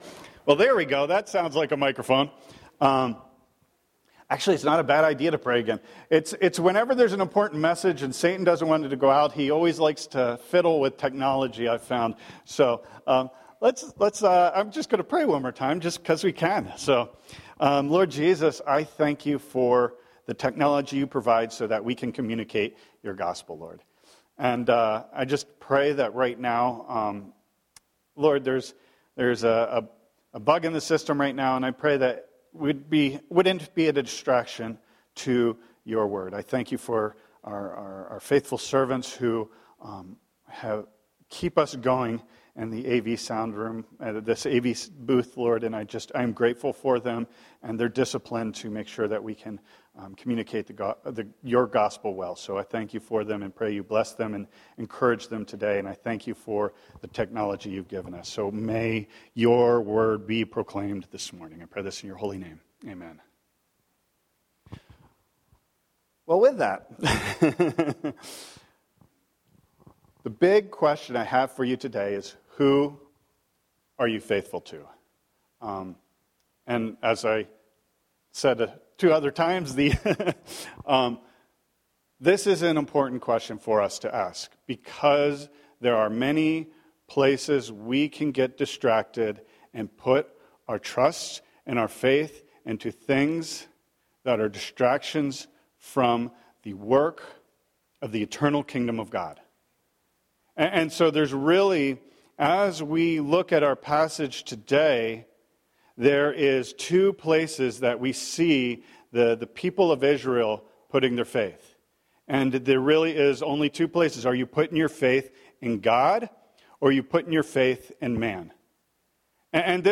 SERMONS - Maranatha Bible Chapel
Sunday-Message-1.19.25.mp3